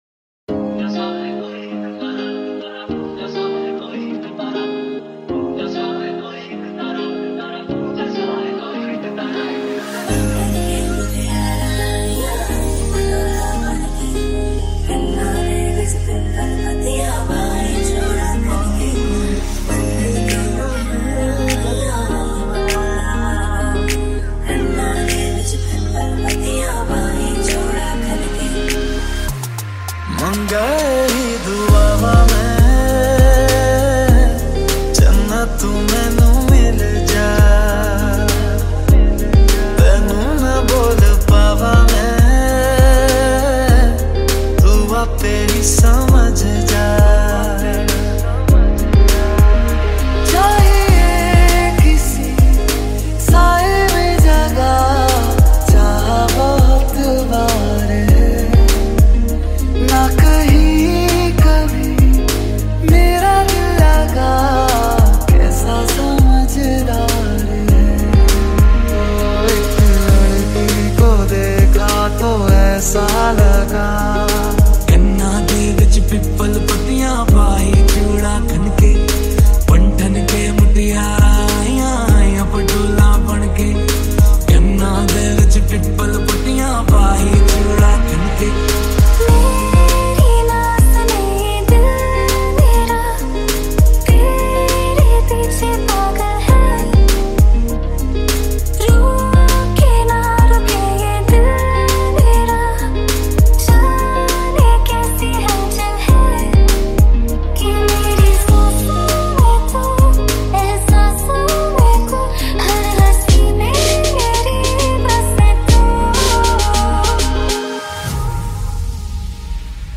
High quality Sri Lankan remix MP3 (3.7).
remix